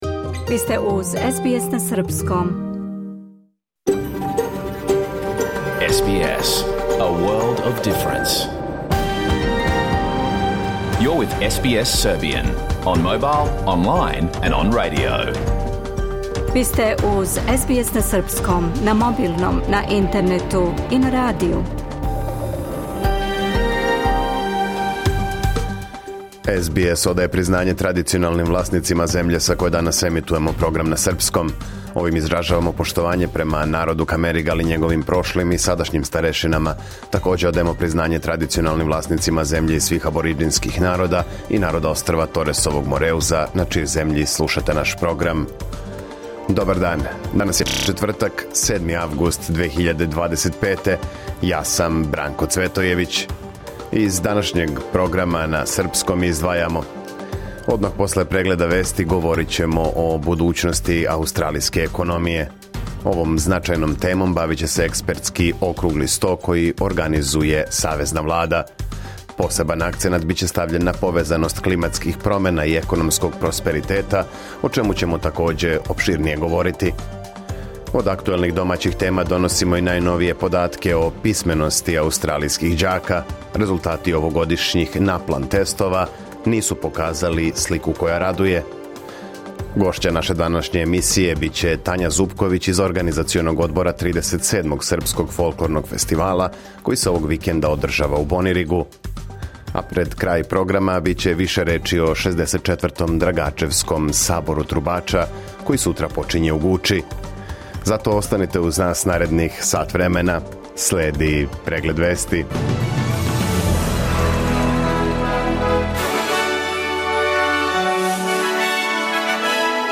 Програм емитован уживо 7. августа 2025. године